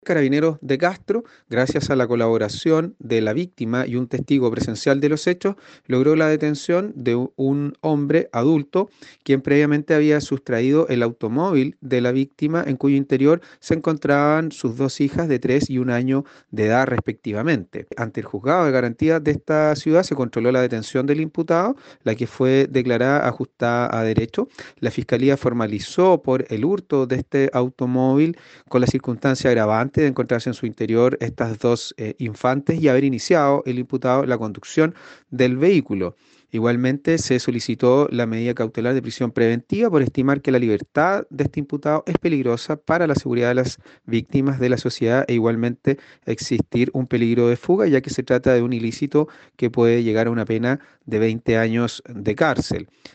El fiscal Fernando Metzner se refirió a la acogida que tuvo el planteamiento del ministerio Público, ya que se otorgó la prisión preventiva para el imputado.